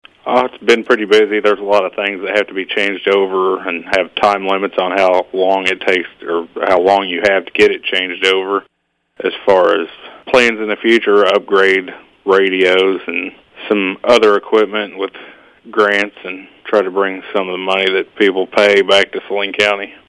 In other news, Kirchhoff spoke about his transition into office and what his goals for the department are.